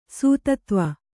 ♪ sūtatva